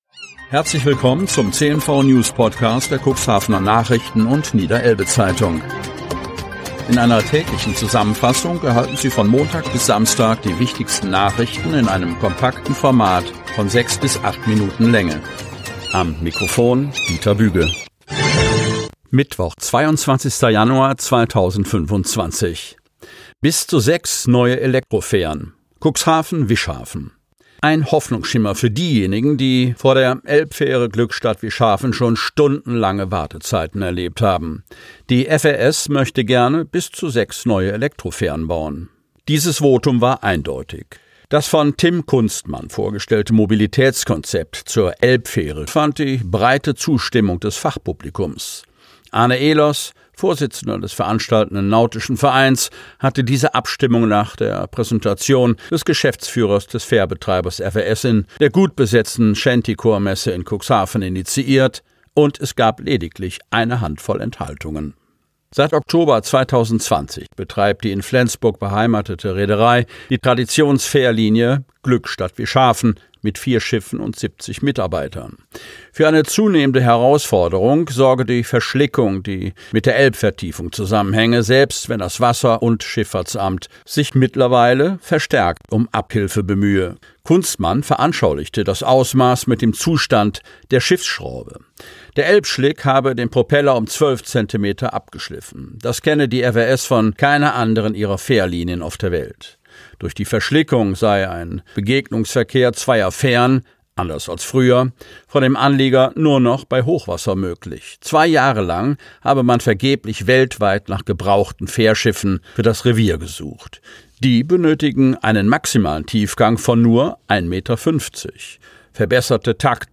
Ausgewählte News der Cuxhavener Nachrichten + Niederelbe-Zeitung am Vorabend zum Hören!